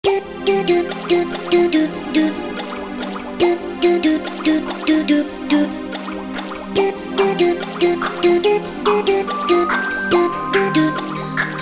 • Качество: 320, Stereo
без слов
детские